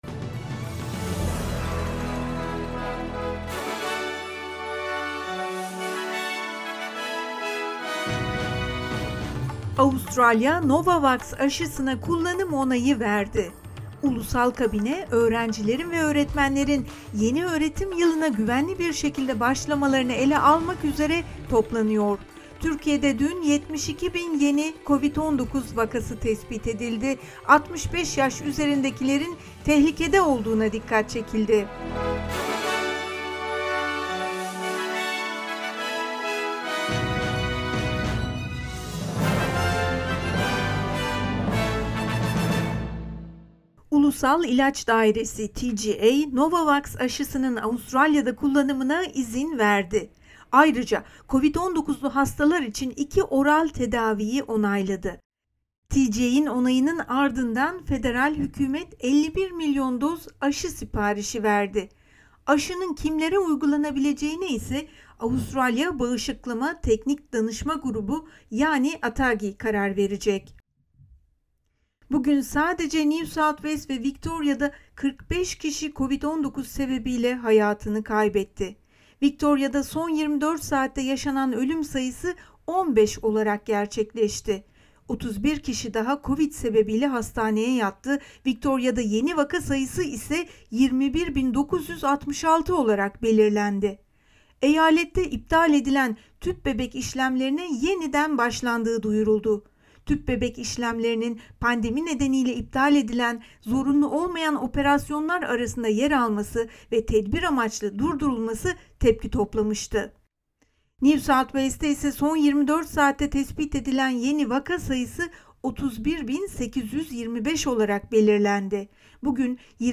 SBS Türkçe Haber Bülteni 20 Ocak